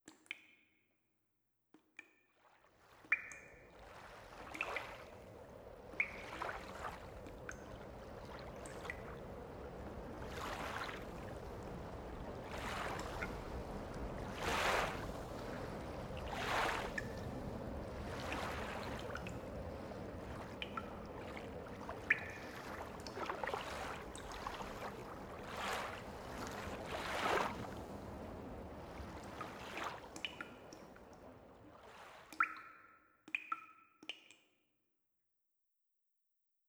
The poem was written in Cornwall, UK, and the voice recorded in Charlottesville, VA, USA.
RelectAudio4loop_slow.wav